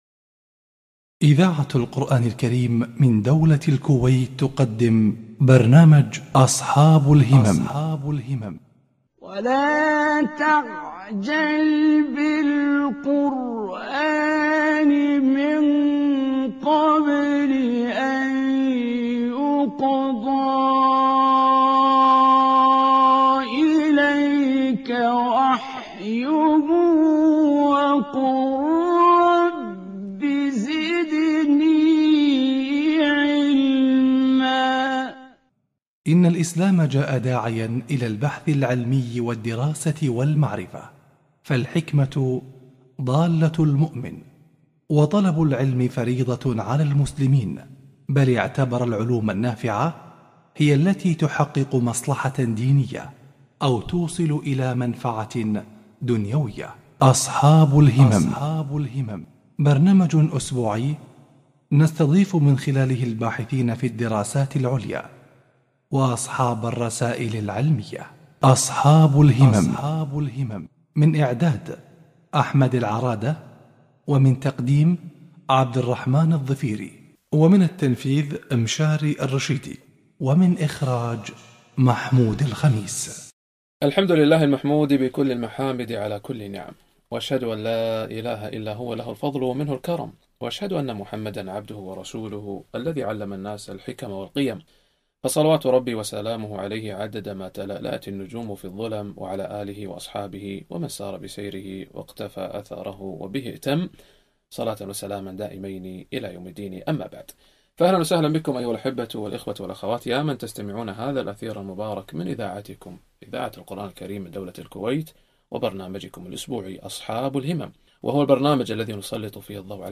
لقاء إذاعة القرآن الكريم برنامج أصحاب الهمم